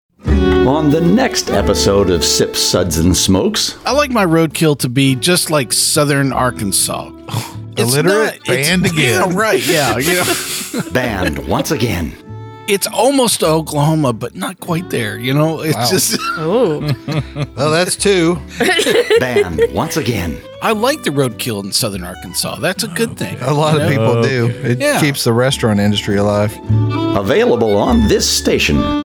192kbps Mono